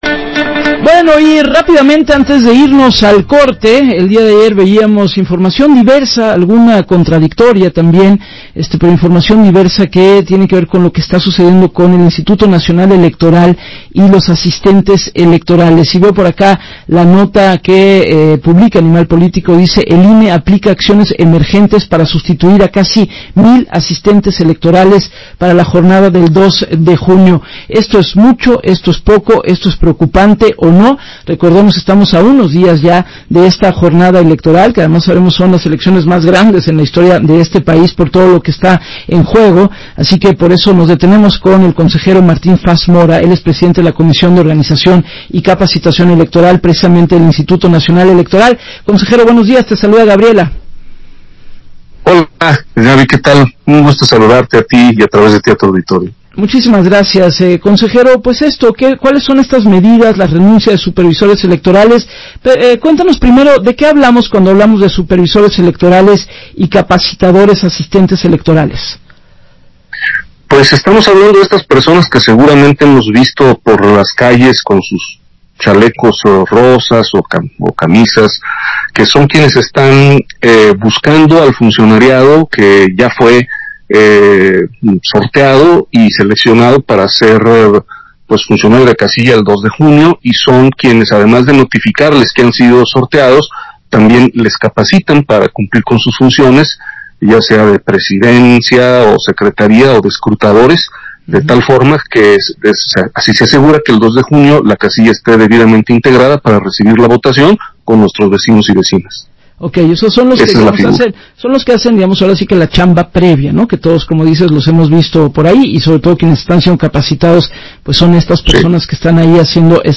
Escucha aquí el audio de la entrevista